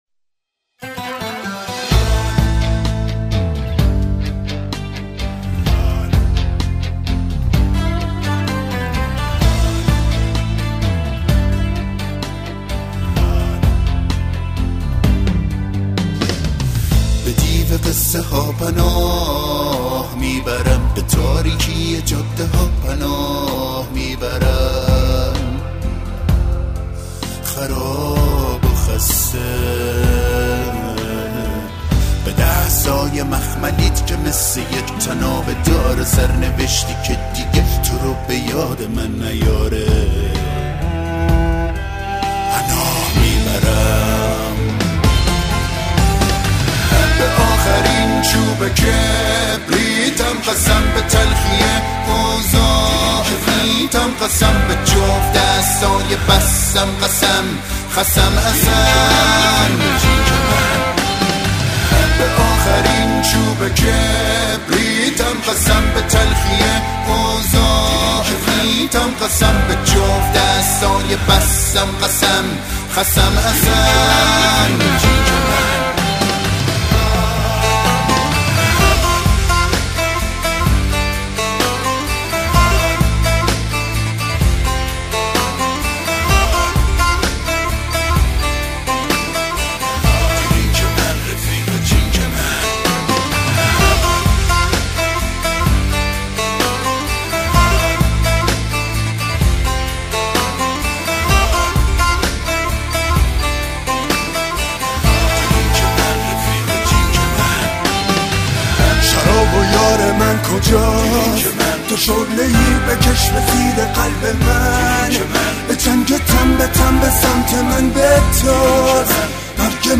دسته : جز و بلوز